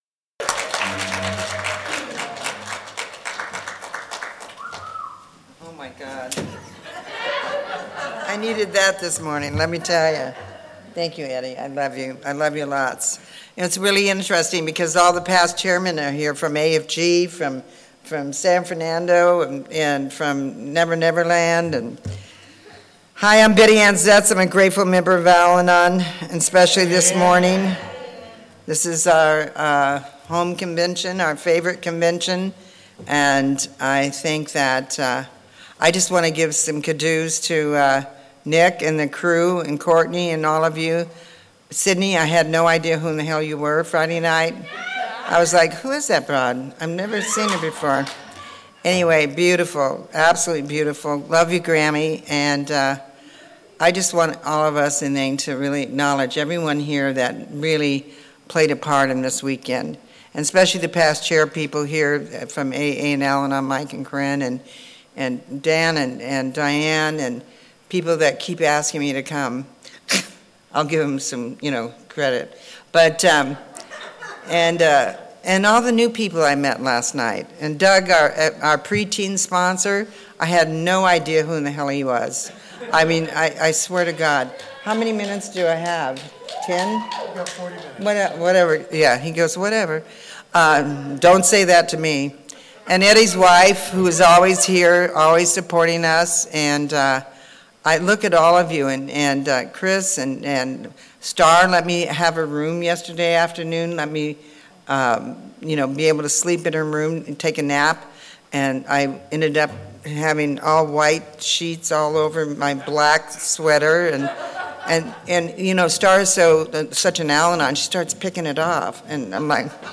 47th Annual San Fernando Valley AA Convention
Sunday Closing Al-Anon Meeting &#8211